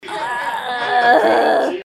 barf_GcDsPHf.mp3